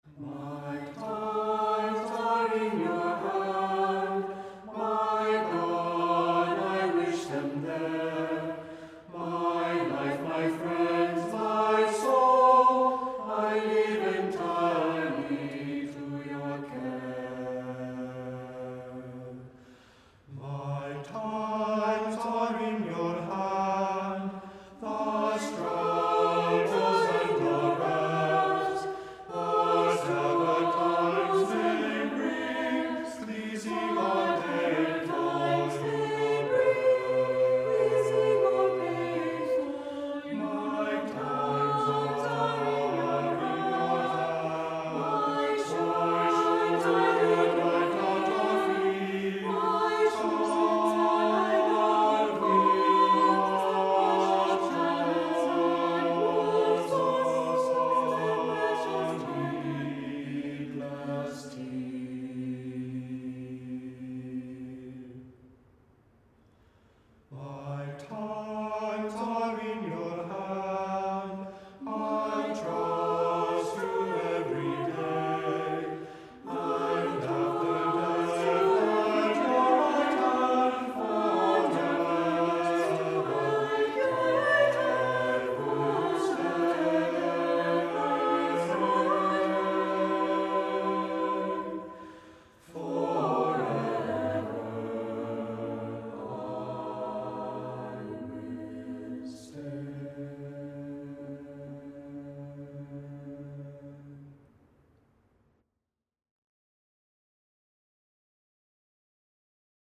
Voicing: Three-part mixed